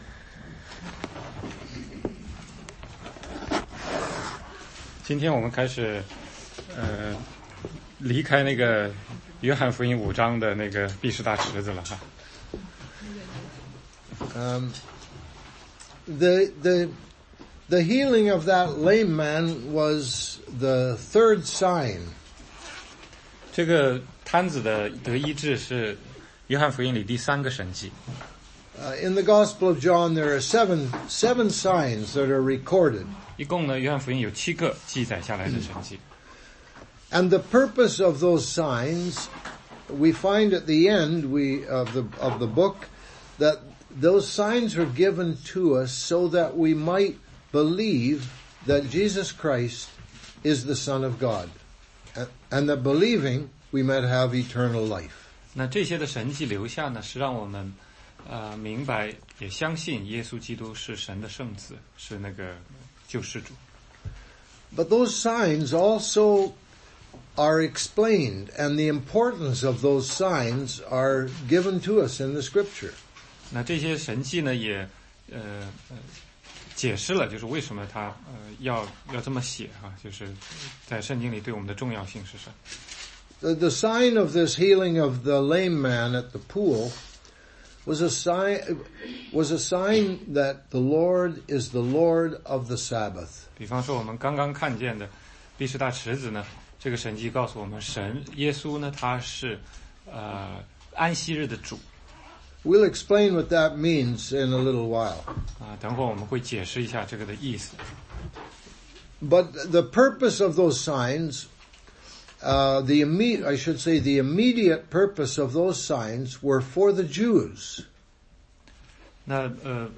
16街讲道录音 - 约翰福音5章18-29